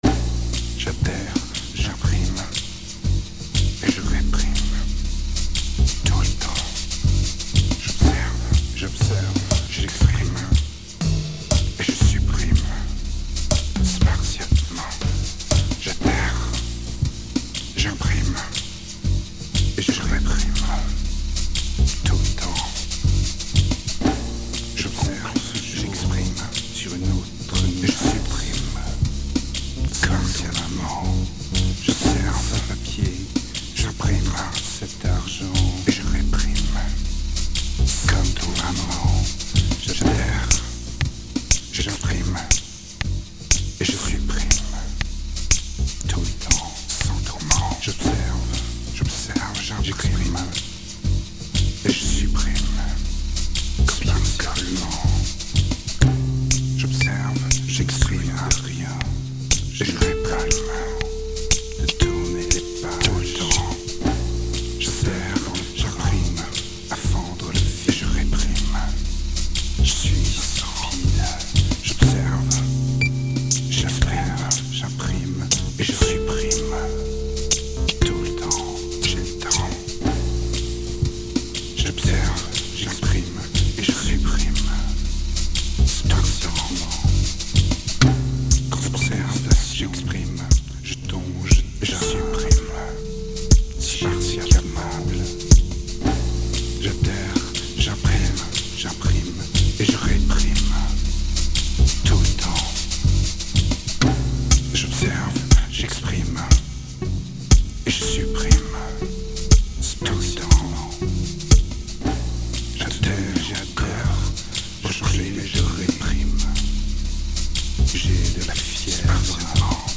Tight and progressive, it seems to flow out more naturally.
2219📈 - -3%🤔 - 120BPM🔊 - 2008-11-03📅 - -309🌟